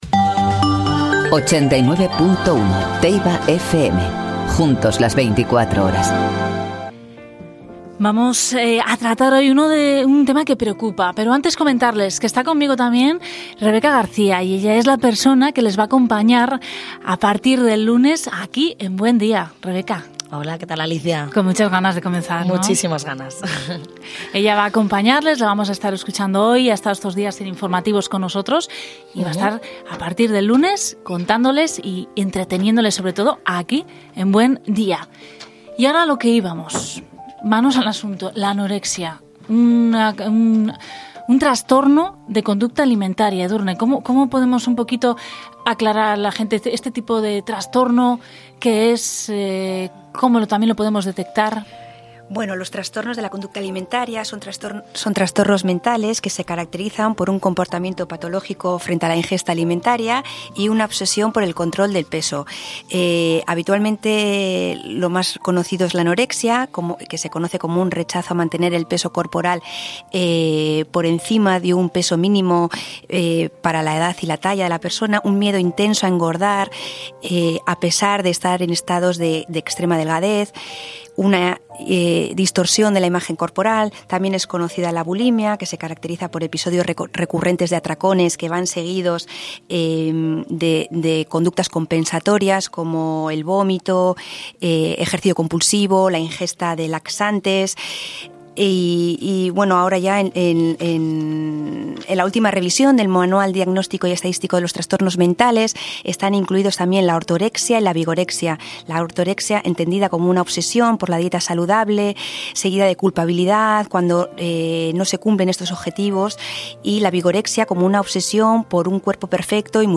en un coloquio sobre este tema